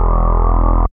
74.08 BASS.wav